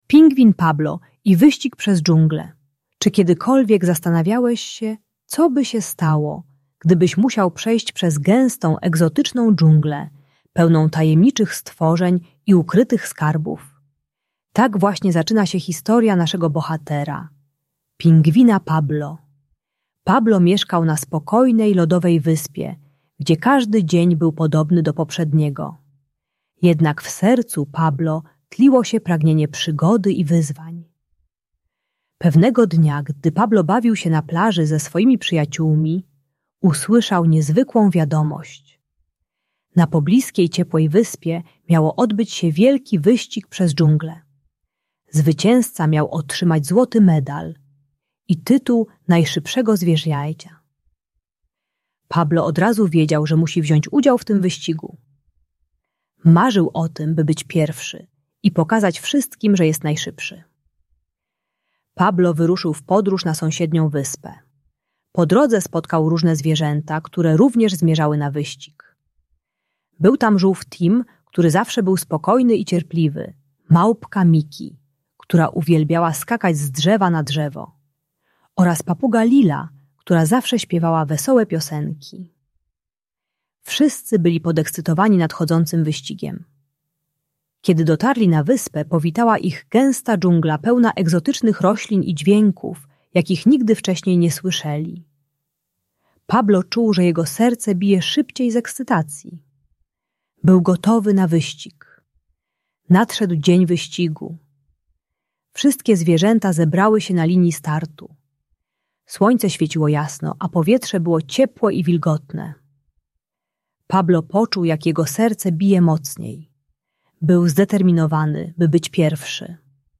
Uczy, że prawdziwa wygrana to nie bycie pierwszym za wszelką cenę, ale pomaganie innym i cieszenie się wspólną zabawą. Audiobajka o radzeniu sobie z frustracją przy przegrywaniu.